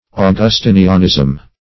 Meaning of augustinianism. augustinianism synonyms, pronunciation, spelling and more from Free Dictionary.
Search Result for " augustinianism" : The Collaborative International Dictionary of English v.0.48: Augustinianism \Au`gus*tin"i*an*ism\, Augustinism \Au*gus"tin*ism\, n. The doctrines held by Augustine or by the Augustinians.